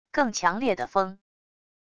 更强烈的风wav音频